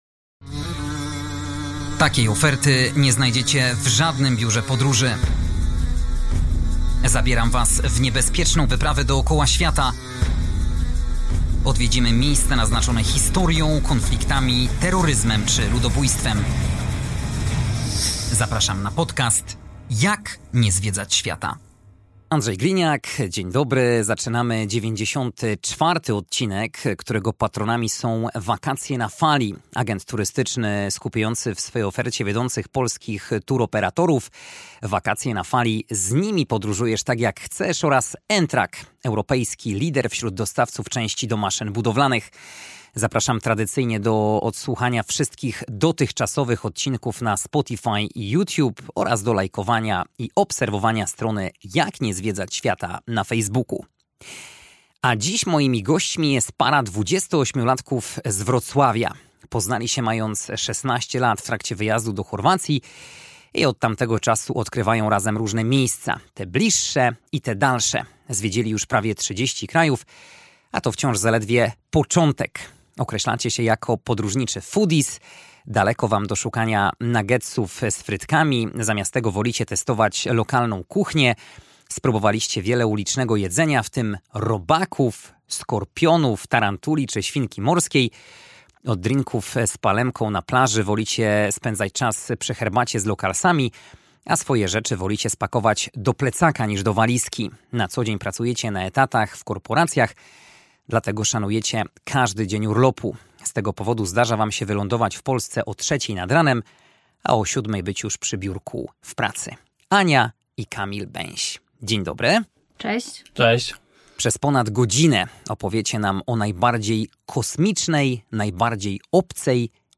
Moim dzisiejszym gościem jest osoba, którą starym słuchaczom na pewno nie muszę przedstawiać, ale zakładam, że i nowi go znają.